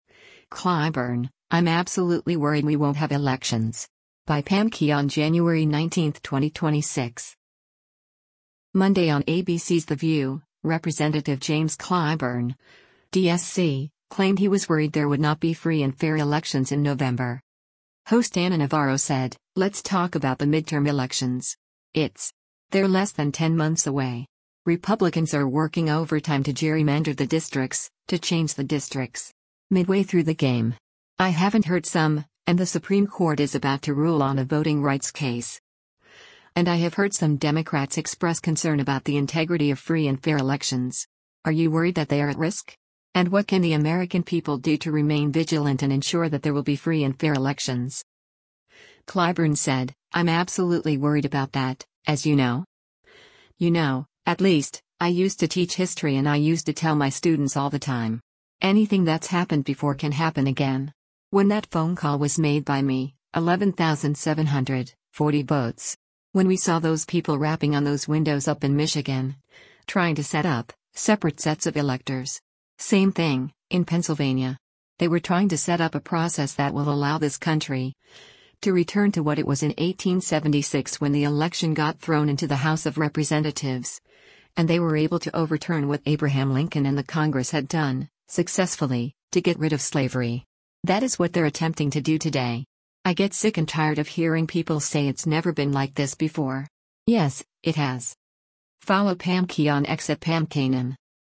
Monday on ABC’s “The View,” Rep. James Clyburn (D-SC) claimed he was worried there would not be free and fair elections in November.